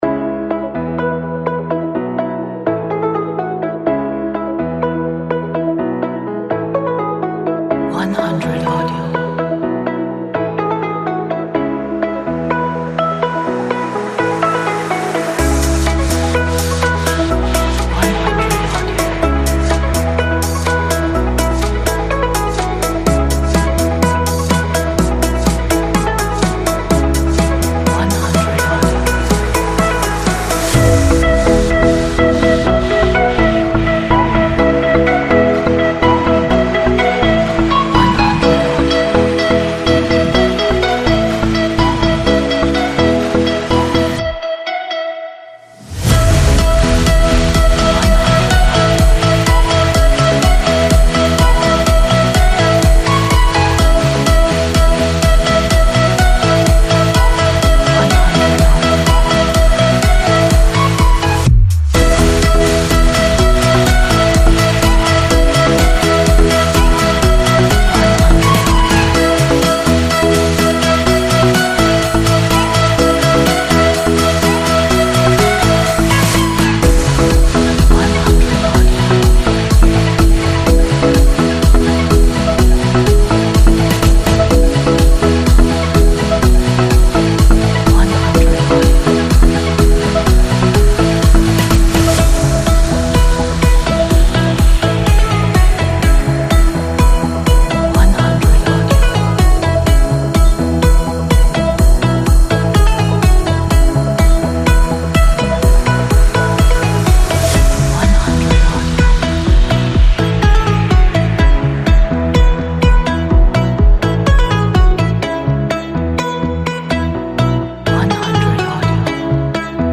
It's extremely crazy and climax.